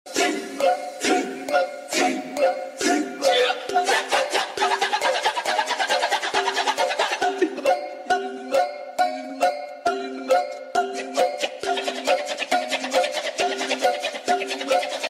Tarian kecak bali karnaval pucangsari